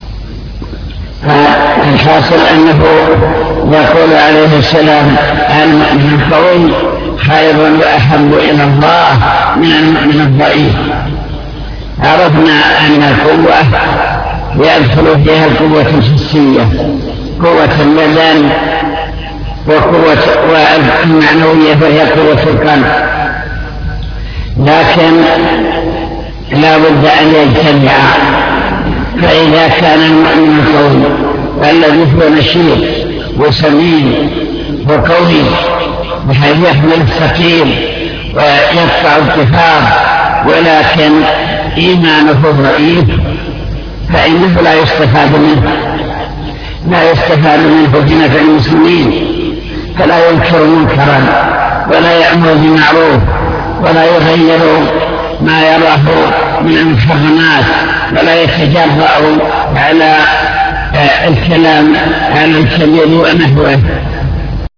المكتبة الصوتية  تسجيلات - كتب  شرح كتاب بهجة قلوب الأبرار لابن السعدي شرح حديث المؤمن القوي